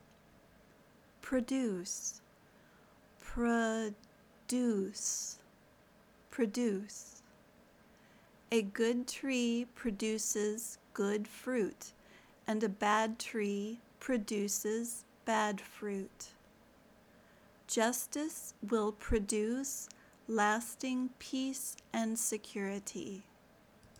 /prə ˈduːs/ (verb)